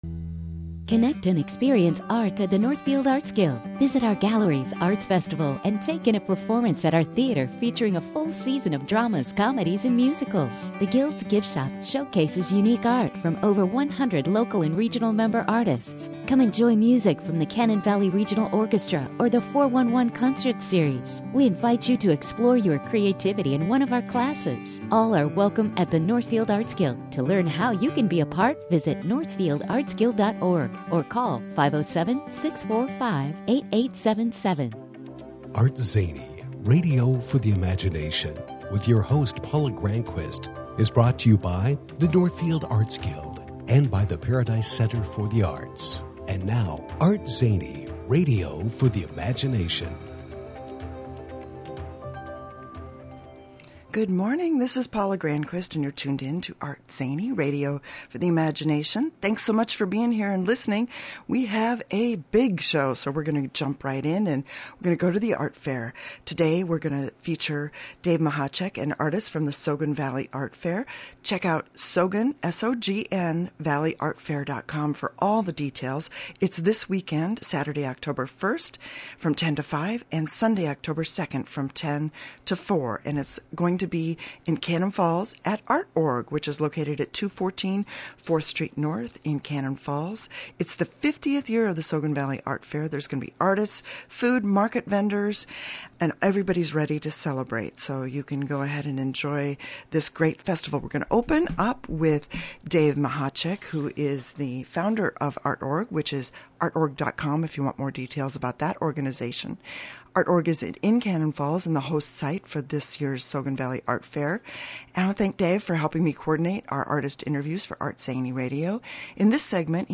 Today in the ArtZany Radio studio